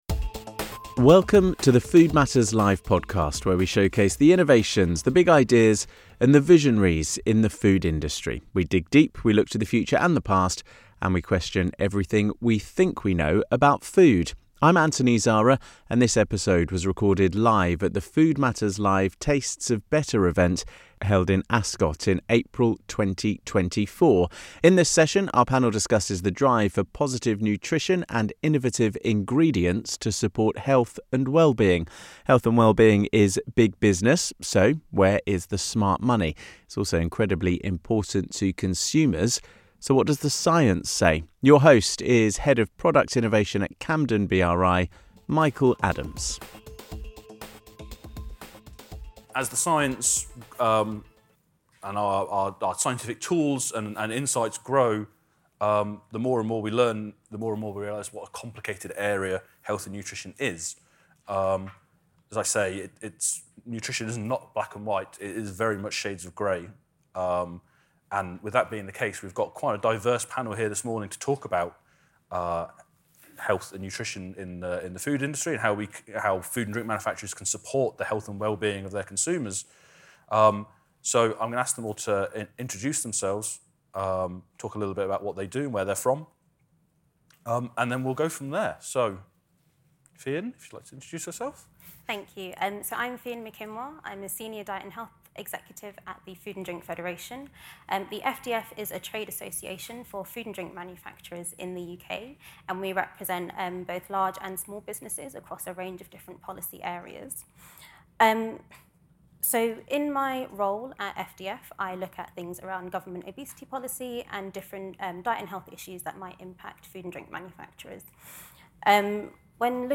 In this episode of the Food Matters Live podcast, recording live at our Tastes of Better event in Ascot in 2023, our expert panel off their insights into where the market might be heading.